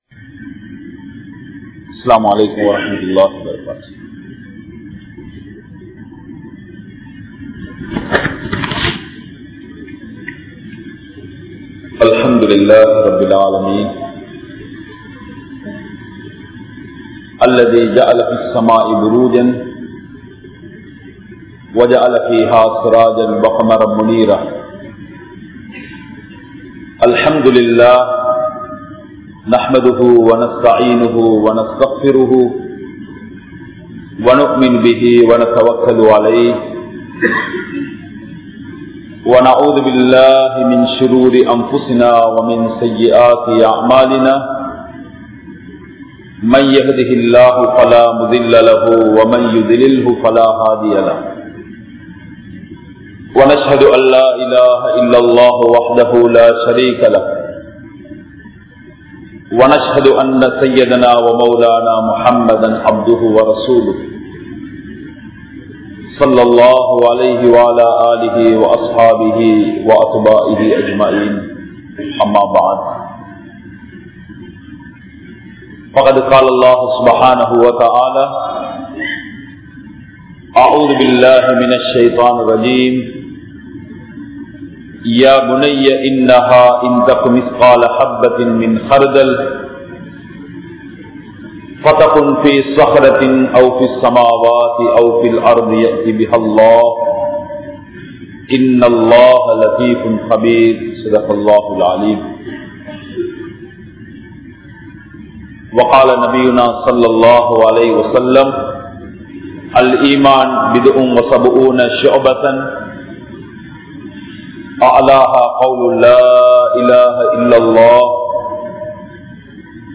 Muslimkal Theevira Vaathihal Illai (முஸ்லிம்கள் தீவிரவாதிகள் இல்லை) | Audio Bayans | All Ceylon Muslim Youth Community | Addalaichenai
Dehiwela, Muhideen (Markaz) Jumua Masjith